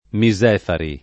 [ mi @$ fari ]